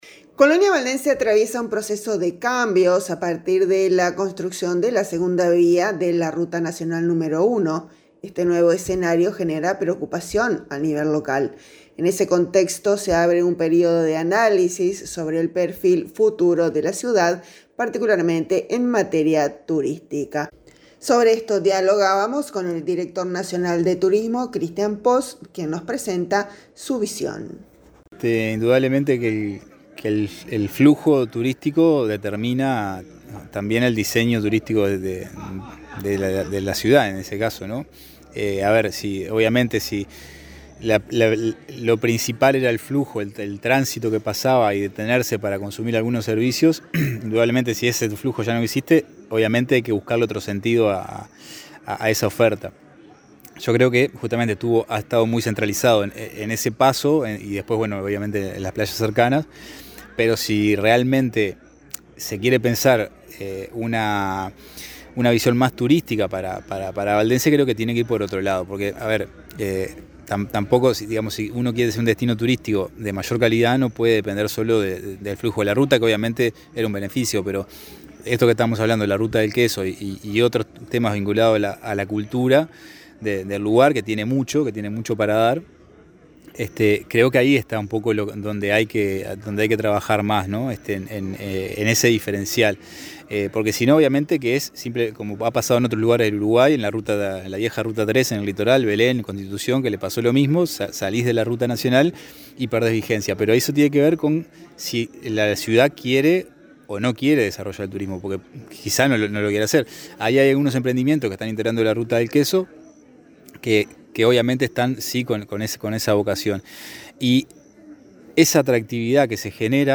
Así dialogábamos con Cristian Pos.